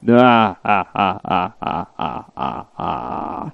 Risada Sem Graça Masculina
Risada sem graça e sem vontade de um homem.
risada-sem-graca.mp3